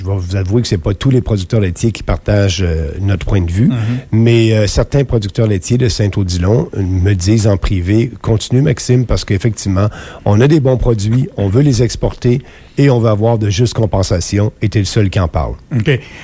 Au micro de Radio Beauce, M. Bernier dit vouloir abolir les frais que les producteurs laitiers doivent encaisser par bête.